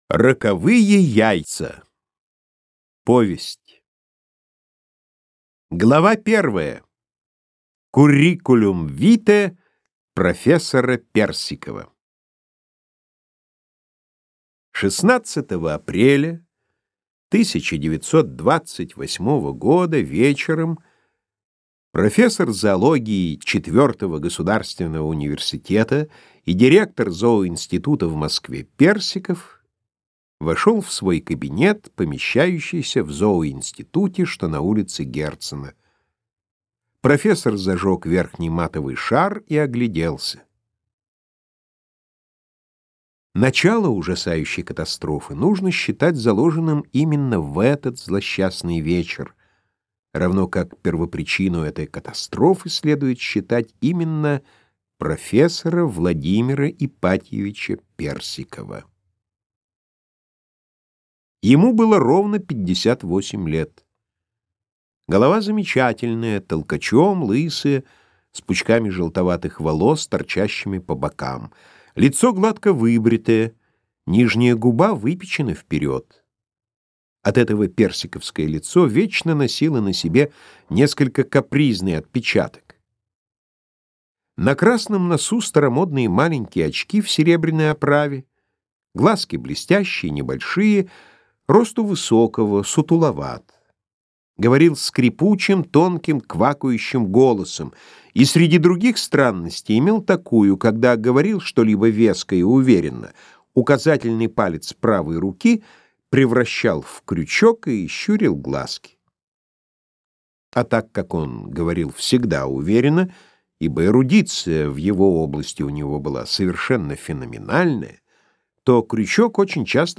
Аудиокнига Собачье сердце - купить, скачать и слушать онлайн | КнигоПоиск